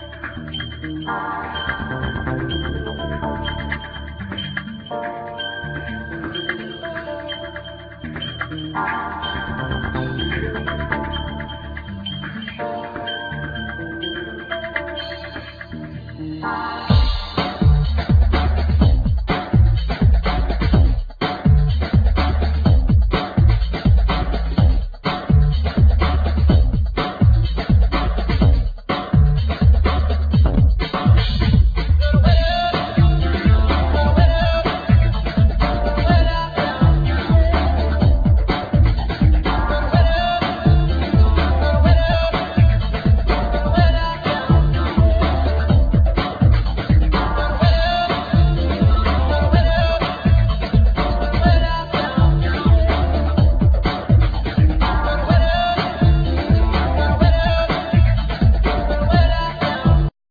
Trumpet
Turntables
Drums
Bass
Fender piano
Synthsizer, Hammond organ
African percussions
Guitar